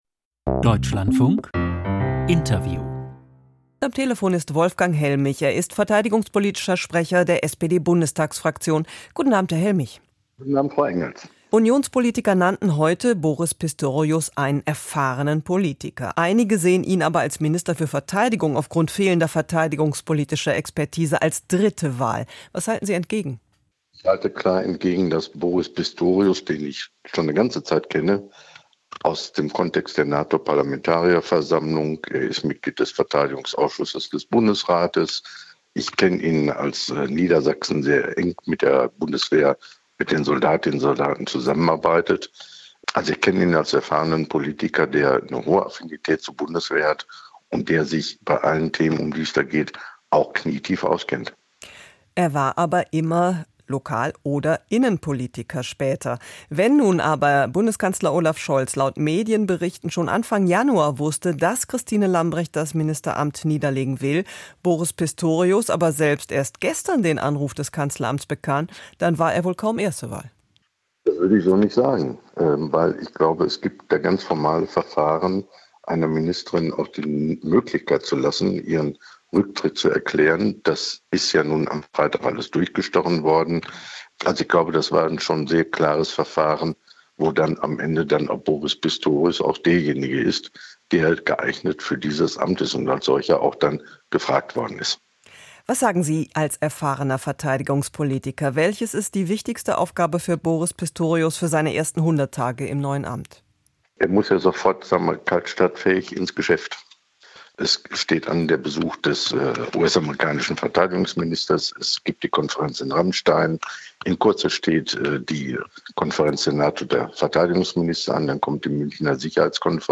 Es wurde am 1. Januar 1962 als Informations- und Nachrichtensender gegründet. Thematische Schwerpunkte des Radios sind heute einerseits Informationen und Hintergrundberichte, andererseits kulturorientierte Sendungen. Der Musikanteil ist am Tage verhältnismäßig gering, aber abends und nachts, sowie am Wochenende beträgt er etwa die Hälfte der gesamten Sendezeit.